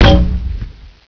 game-source/ParoxysmII/sound/weapons/grenade.wav at cb5e8084cfc840762e0e335e3cc2d195f3904e5e
grenade.wav